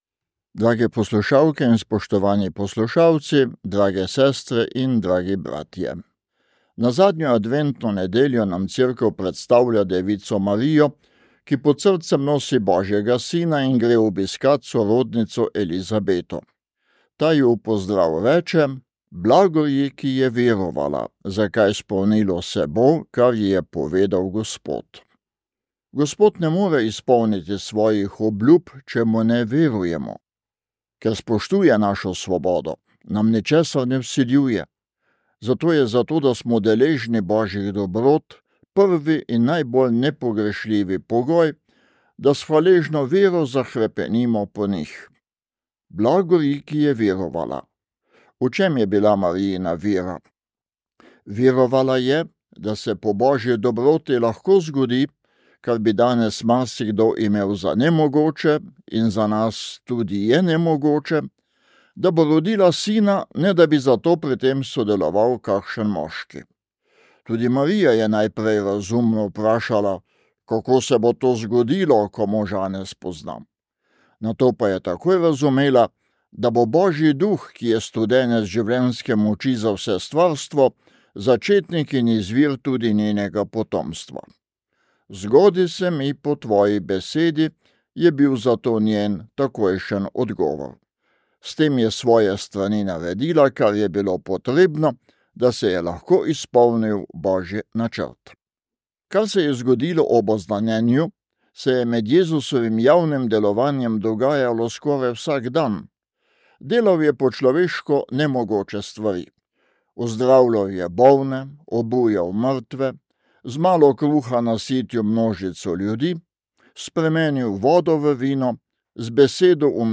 Duhovni nagovor